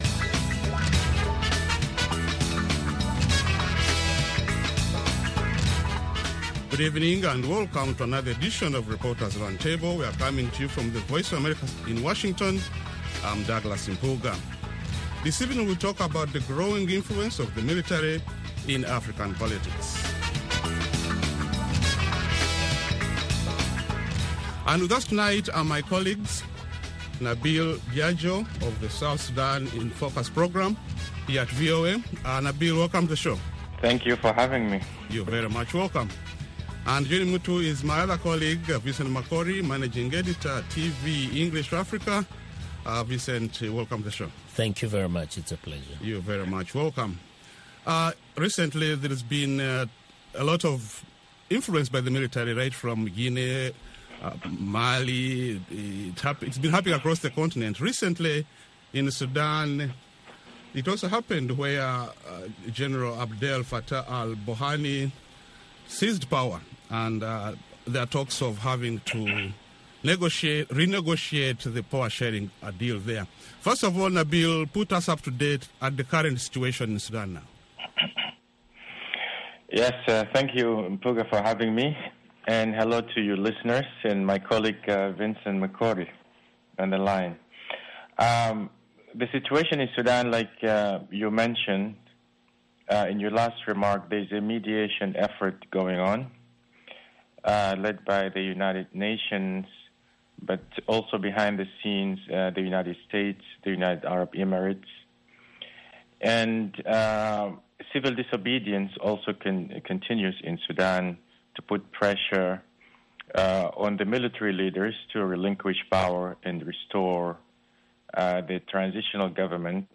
along with a lively panel of journalists, who analyze the week’s major developments in Africa.